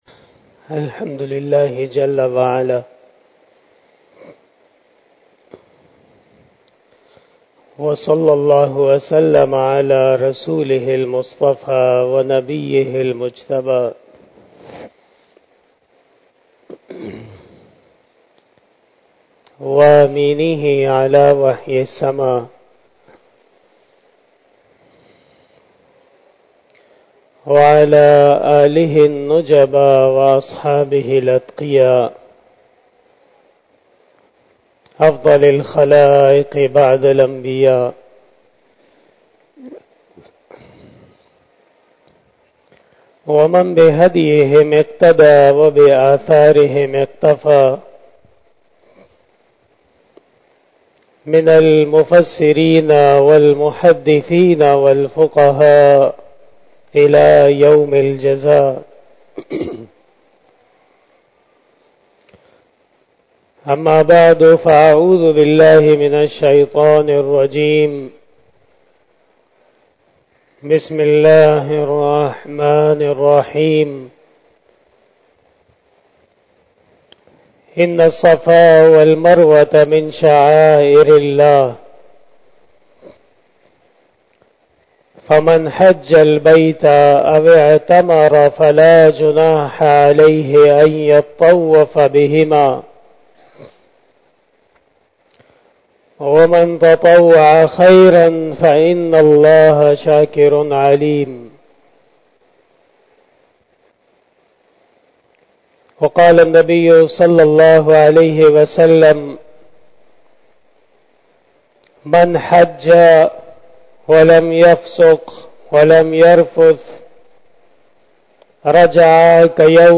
22 BAYAN E JUMA TUL MUBARAK 10 June 2022 (10 Zil Qaadah 1443H)
02:26 PM 430 Khitab-e-Jummah 2022 --